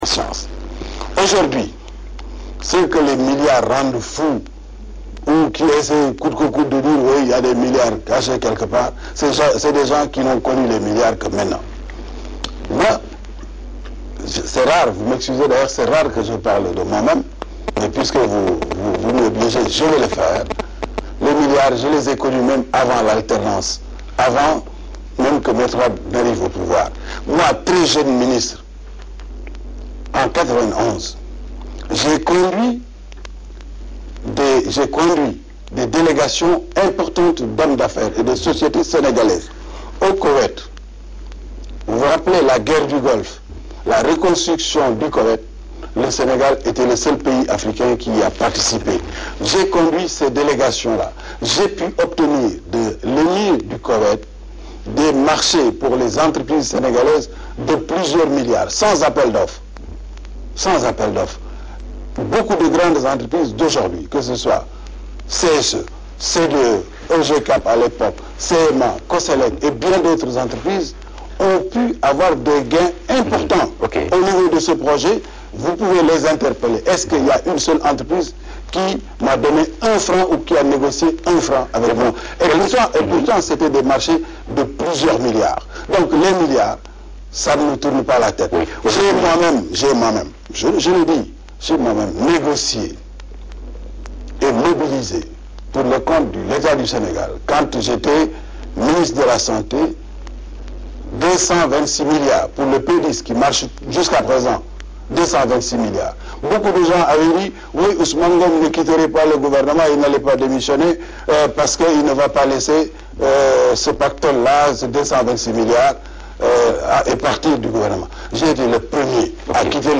Au cours de l’émission Opinion de Walf tv, l’ancien ministre de l’Intérieur qui est cité au cœur du scandale des passeports numérisés, est revenu en long et en large sur la provenance de sa richesse non sans dire à qui veut l’entendre qu’il n’est pas ébranlé par les milliards.